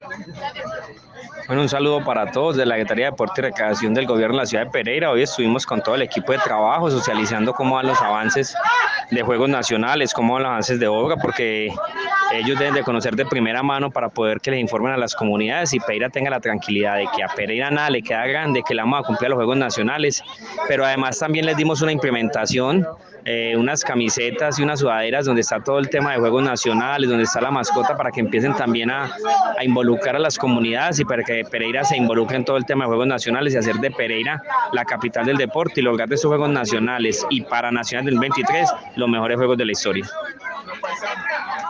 Gustavo_Rivera_Secretario_de_Deporte_y_Recreacion_de_Pereira.02.mp3